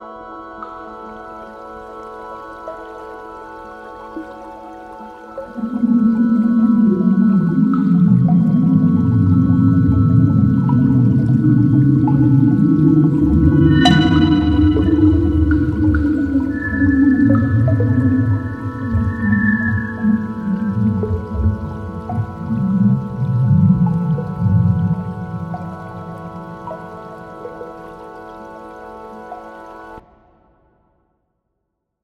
Light towards the top of the image is mapped to higher-pitch sound, with radio, infrared, and X-ray light mapped to low, medium, and high pitch ranges. Bright background stars are played as water-drop sounds, and the location of the binary system is heard as a plucked sound, pulsing to match the fluctuations due to the orbital dance.